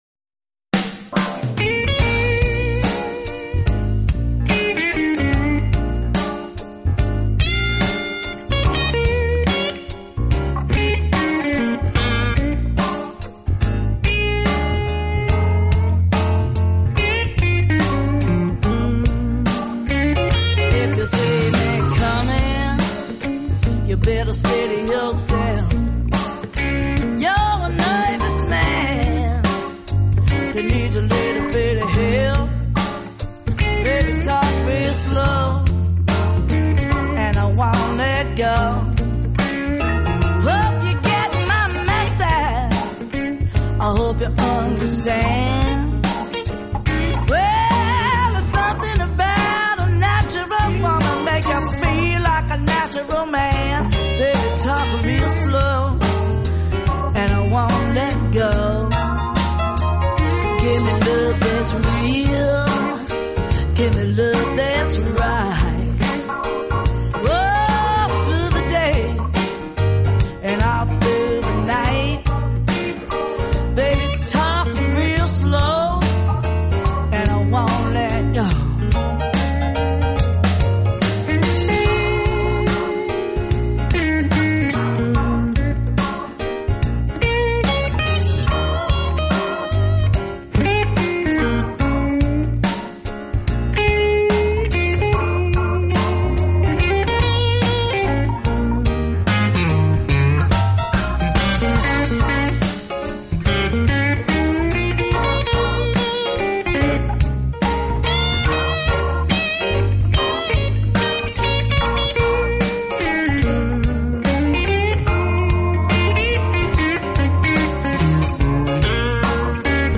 她出色的真假音切换唱法让
人着迷，声音稍为带点沧桑、沙哑，所以在演唱爵士或者是蓝调乐曲时，特别的